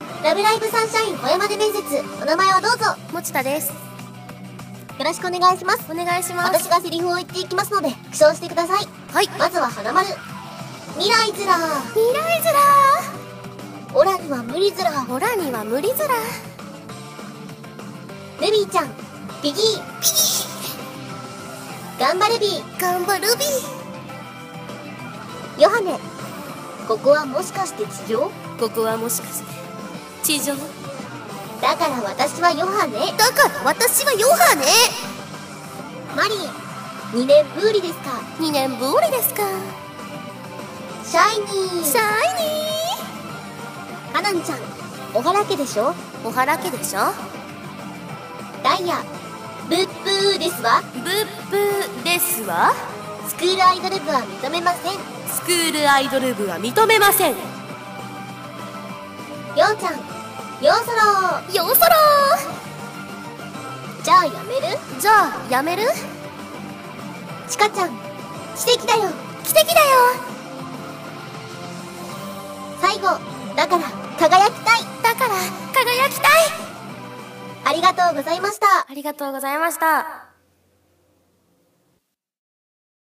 ラブライブ！サンシャイン!!声真似面接